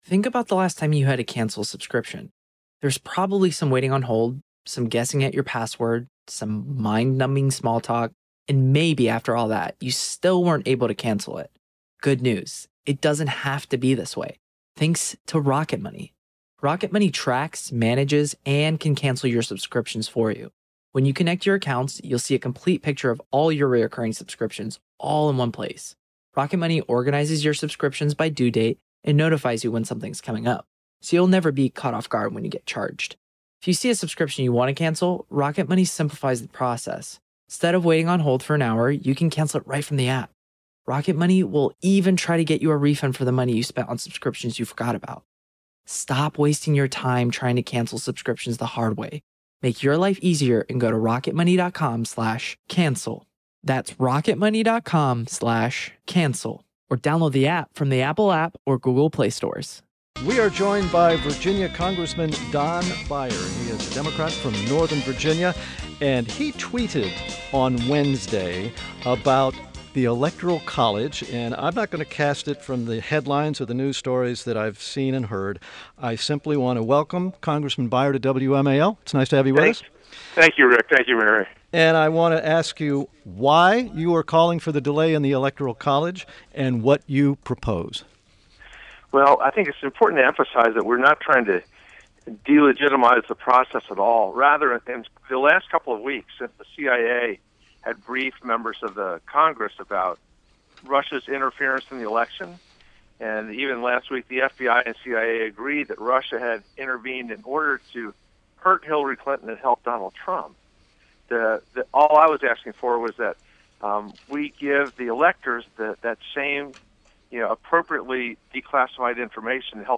INTERVIEW – CONGRESSMAN DON BEYER — (D-VA)